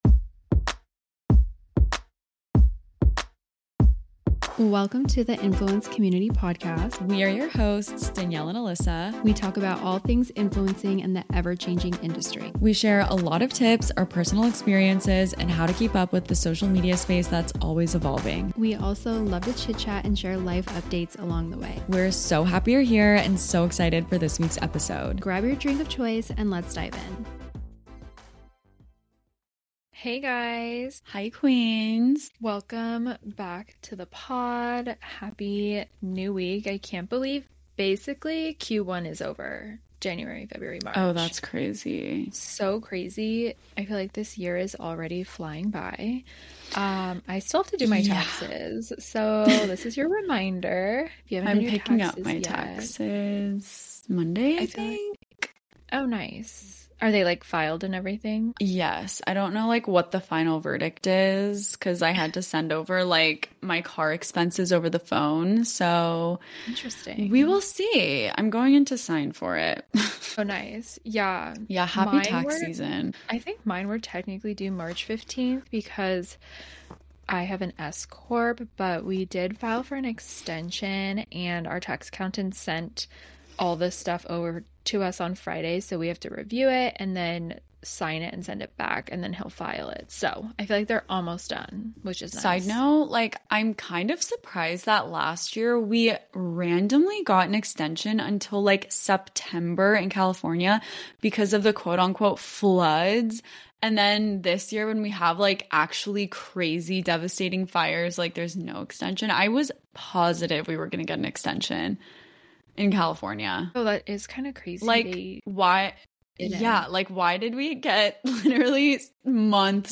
The Influence Community is a weekly podcast hosted by two fashion influencers and passionate entrepreneurs